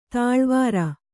♪ tāḷvāra